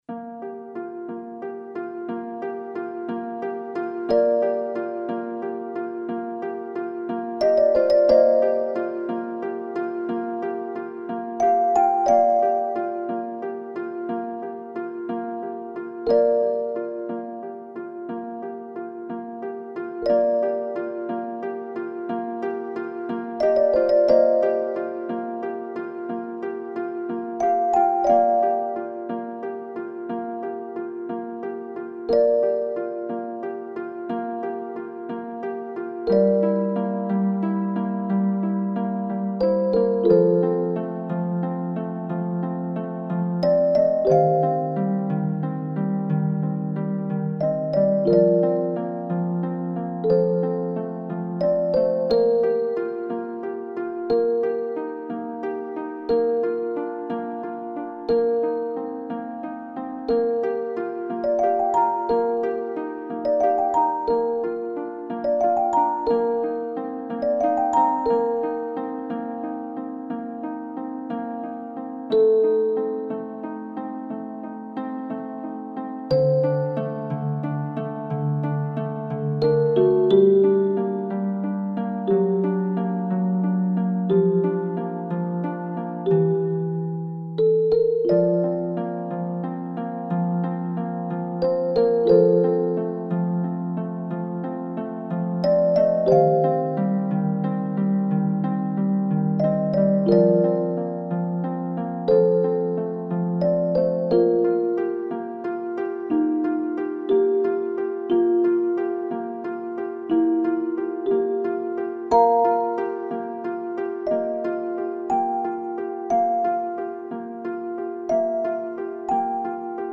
オルゴール曲素材